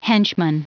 Prononciation du mot henchman en anglais (fichier audio)
Prononciation du mot : henchman